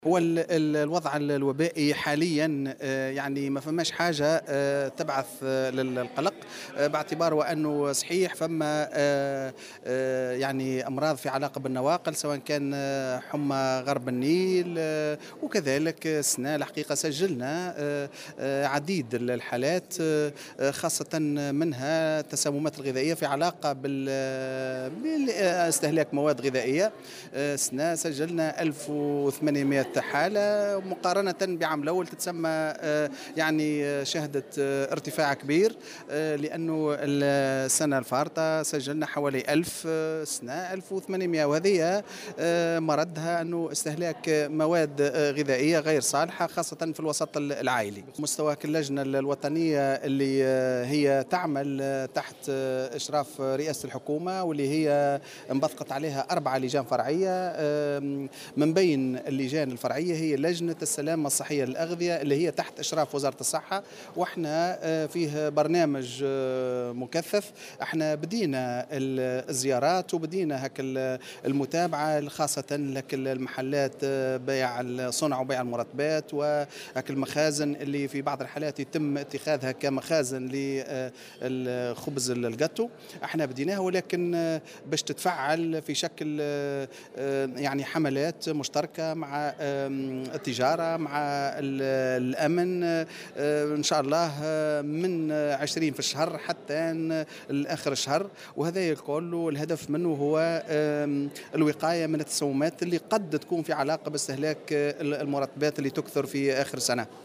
وأضاف في تصريح اليوم لمراسلة "الجوهرة أف أم" على هامش انطلاق الايام الوطنية في دورتها 22 لحفظ الصحة، أنه تم خلال السنة الحالية تسجيل 1800 حالة تسمم غذائي في الوسط العائلي، مقارنة بحوالي ألف حالة خلال السنة الماضية.